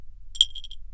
bell 3
bell bells clink ding jingle jingle-bell ring ting sound effect free sound royalty free Sound Effects